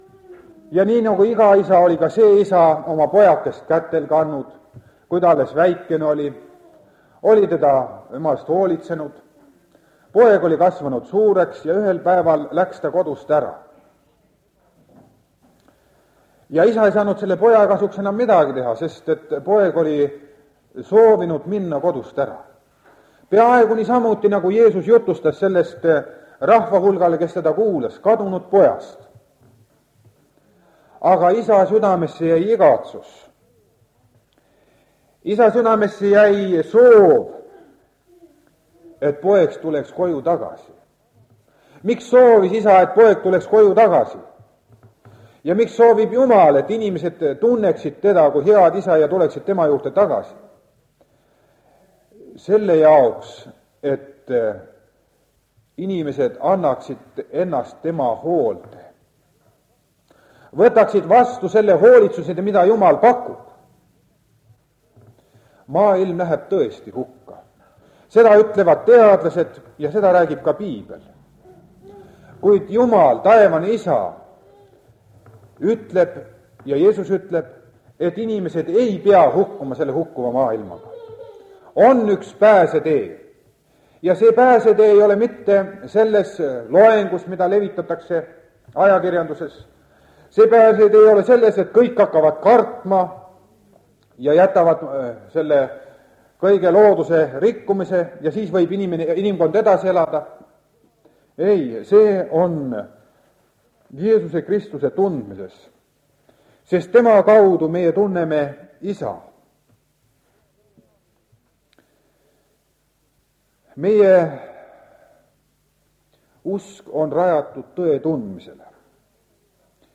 Jultlus lintmaki lindilt on pisut ilma alguseta aga heade mõtetega. KADUNUD POEG JA TÕE OTSIMINE Mis on tõde?
Lisaks on ka üks laul dueti esituses...